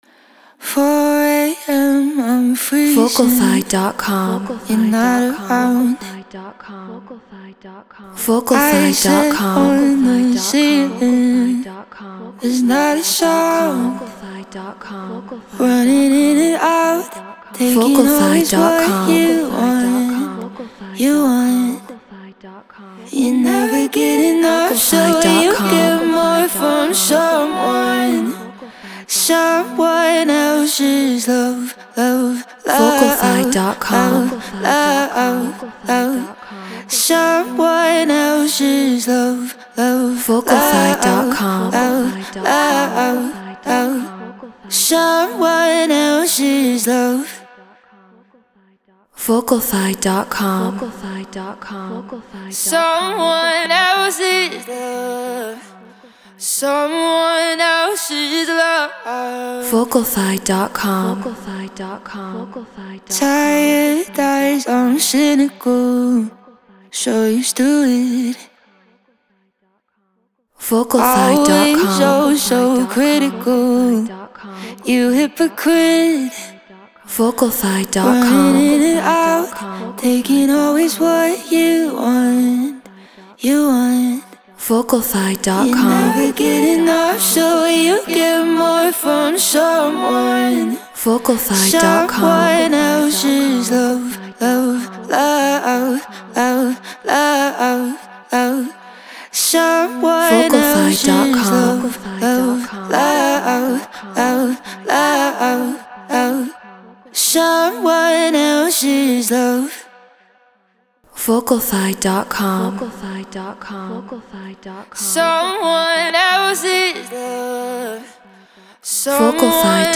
Get Royalty Free Vocals.
Non-Exclusive Vocal.